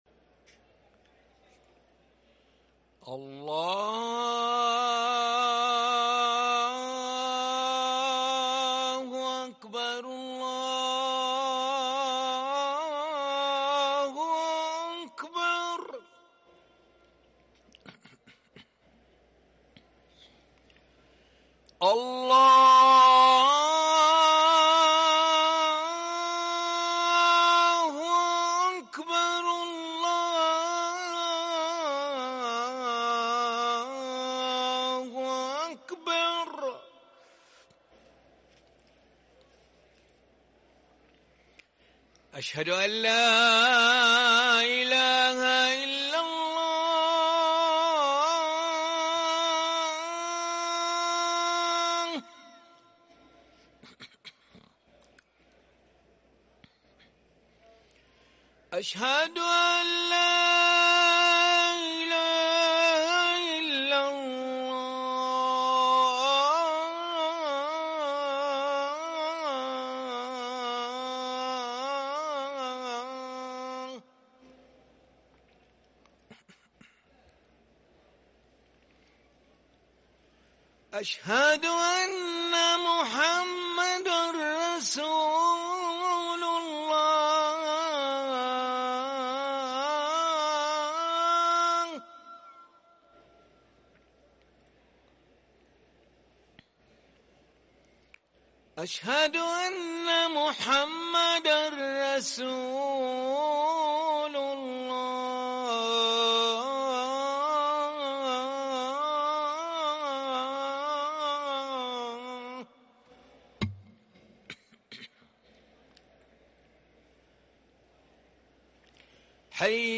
اذان العشاء للمؤذن علي ملا الخميس 13 محرم 1444هـ > ١٤٤٤ 🕋 > ركن الأذان 🕋 > المزيد - تلاوات الحرمين